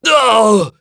Lusikiel-Vox_Damage_03.wav